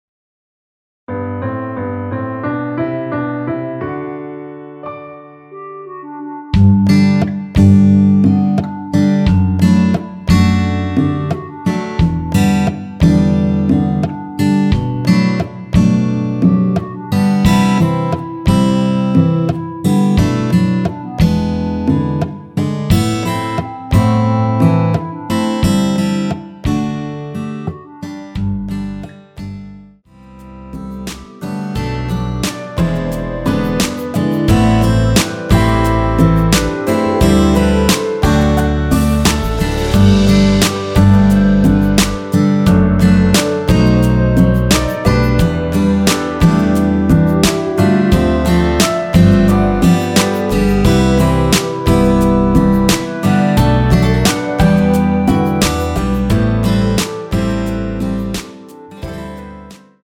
원키에서(-2)내린 멜로디 포함된 MR이며 여자파트 멜로디는 없습니다.(미리듣기 참조)
앞부분30초, 뒷부분30초씩 편집해서 올려 드리고 있습니다.
중간에 음이 끈어지고 다시 나오는 이유는